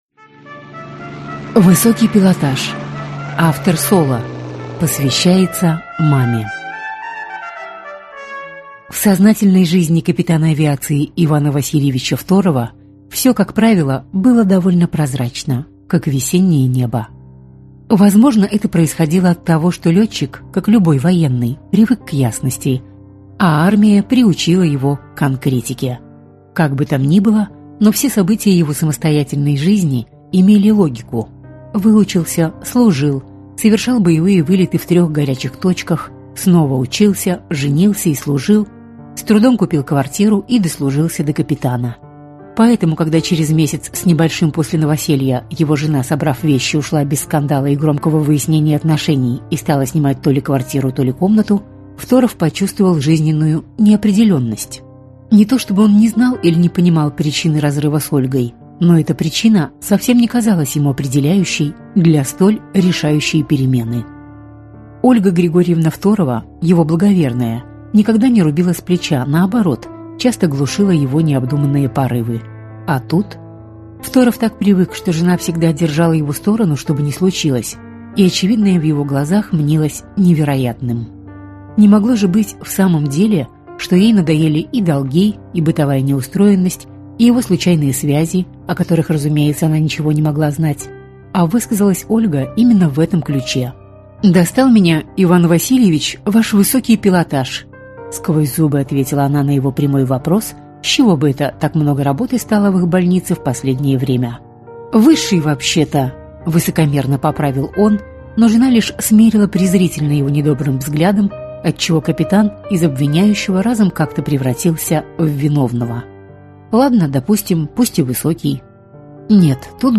Аудиокнига Высокий пилотаж | Библиотека аудиокниг